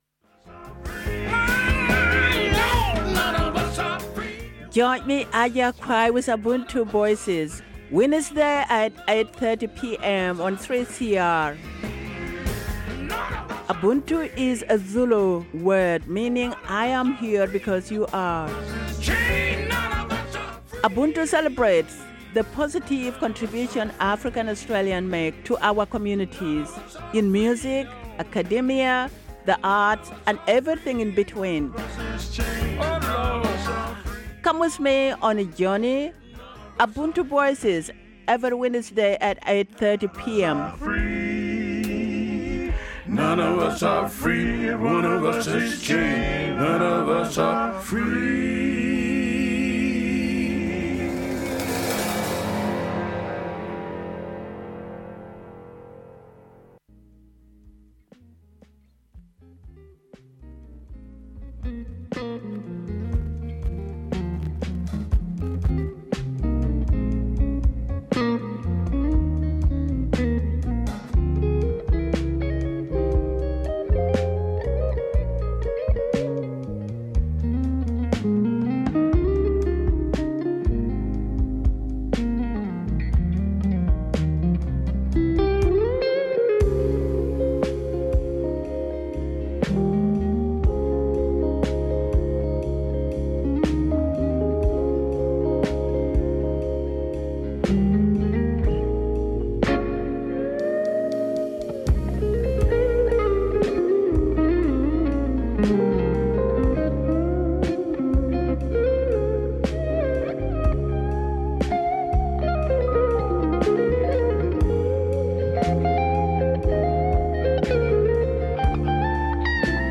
IWD 2023 special broadcast